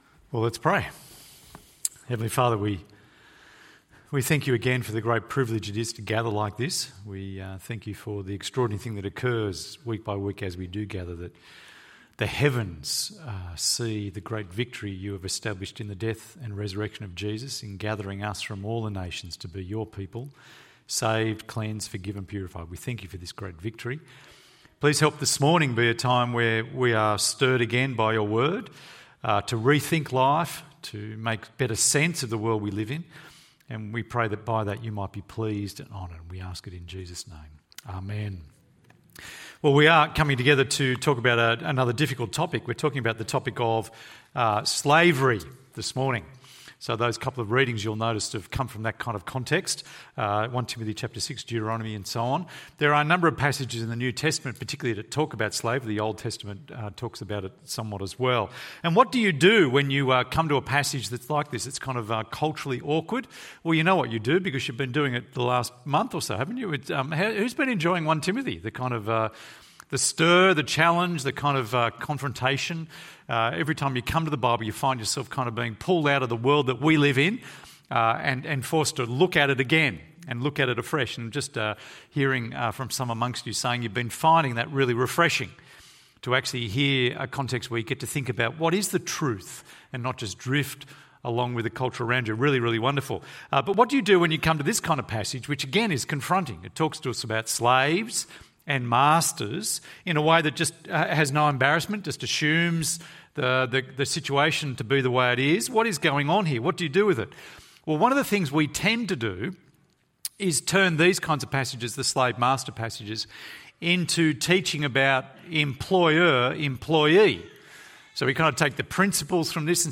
Slaves and Masters ~ EV Church Sermons Podcast